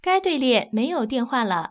ivr-no_calls_waiting_in_queue.wav